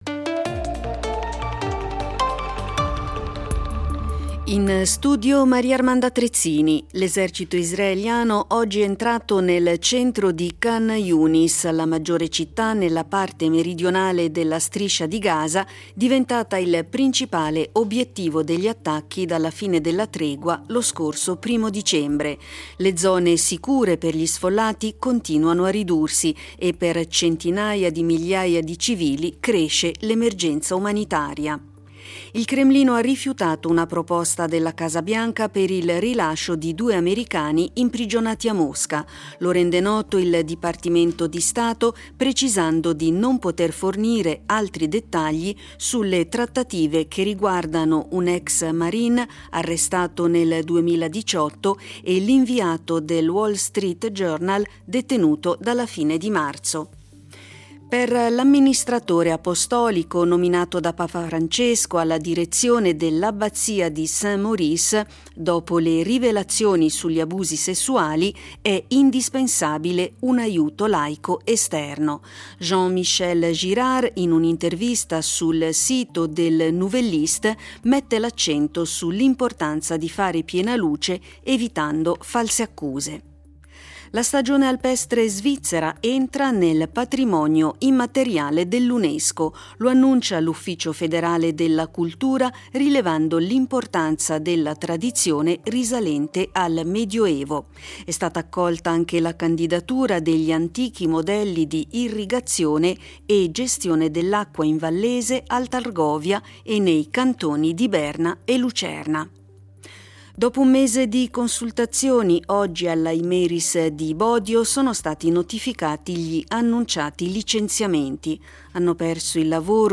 Notiziario delle 21:00 del 05.12.2023